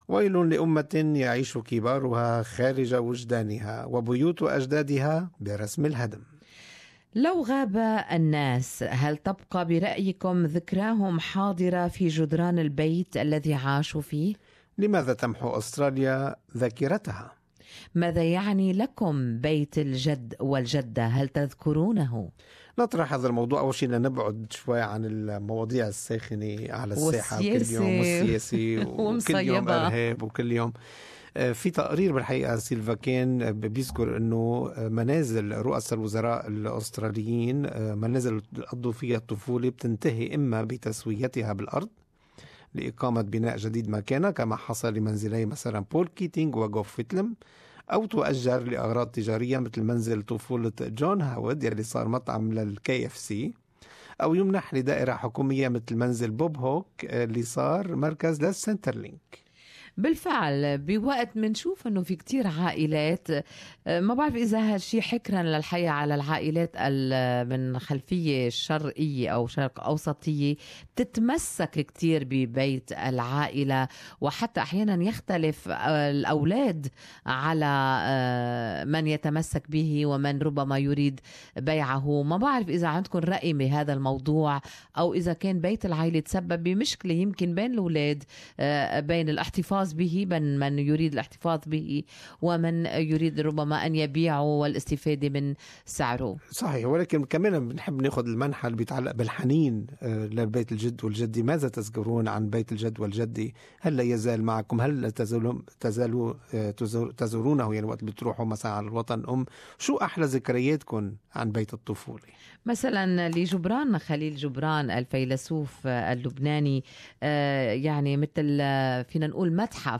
How was your feeling when you stepped in?More in this talkback on Good Morning Australia at SBS Arabic 24.